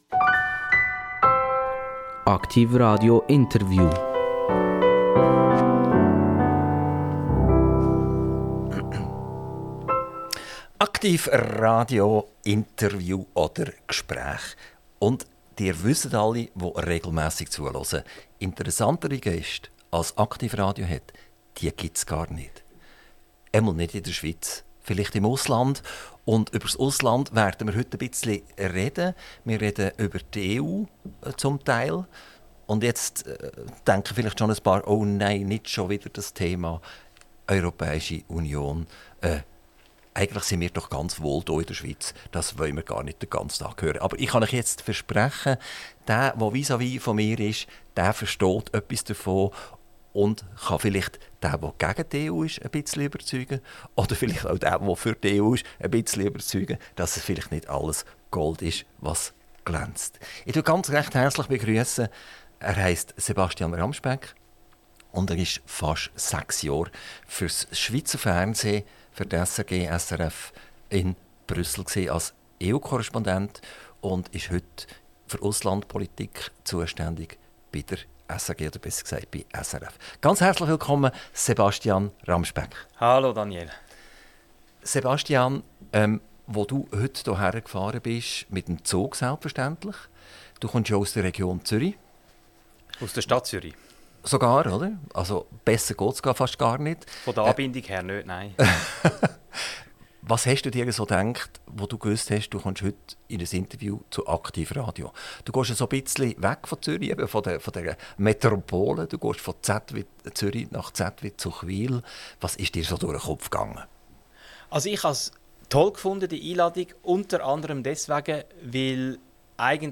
INTERVIEW - Internationaler Korrespondent SRF - 26.04.2024